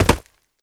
player_jumplandv2.wav